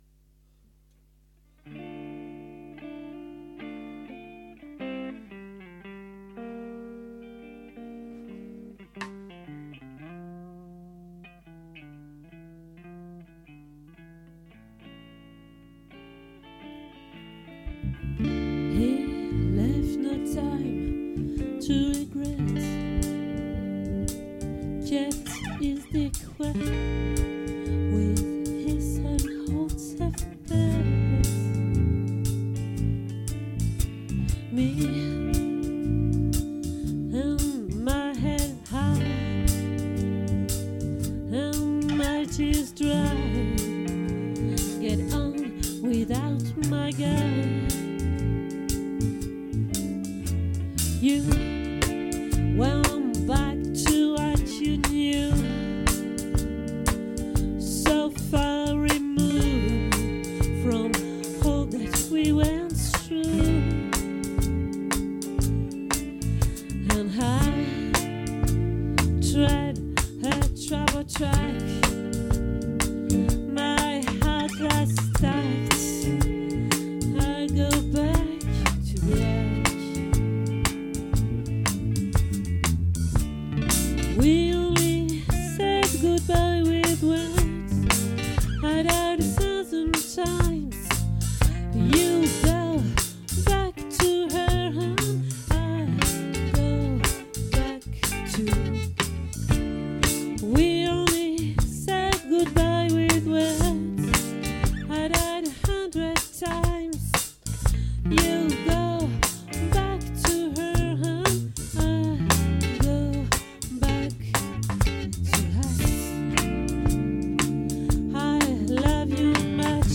🏠 Accueil Repetitions Records_2023_06_14_OLVRE